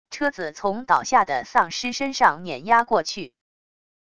车子从倒下的丧尸身上碾压过去wav音频